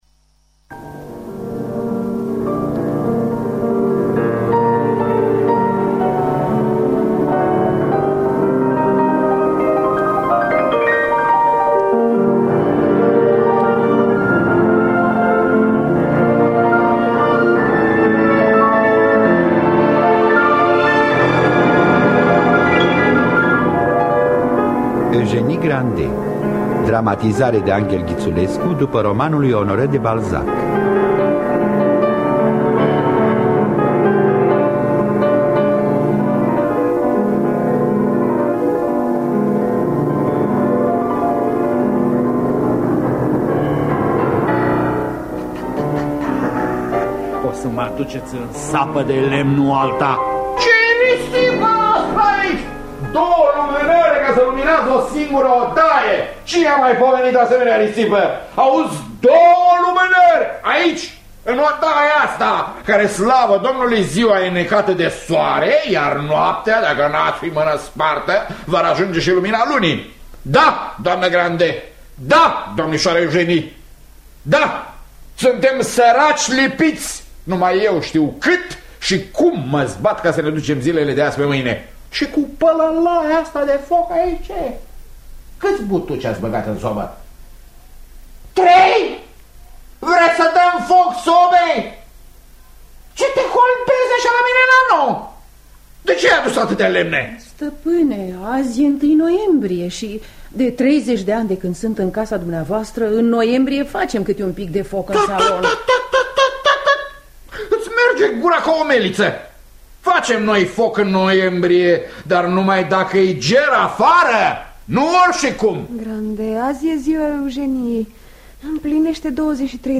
Dramatizarea radiofonică de Anghel Ghiţulescu.